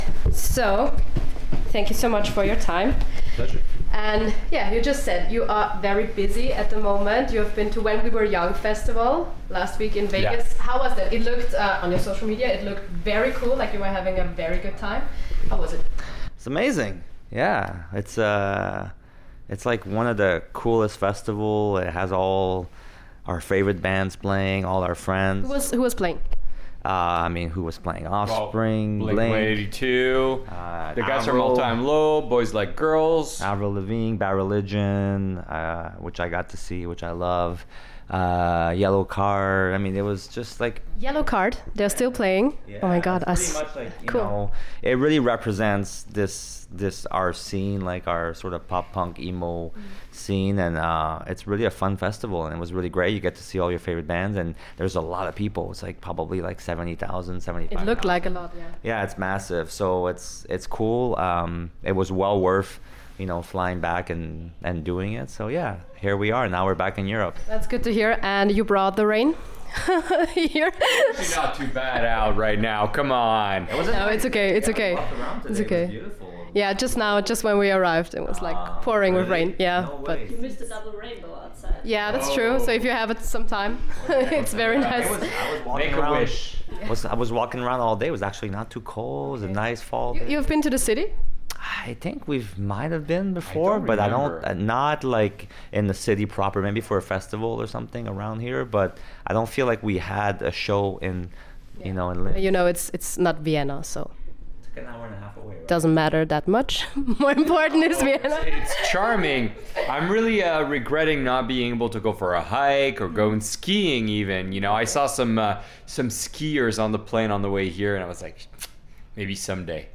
88.6 Interviews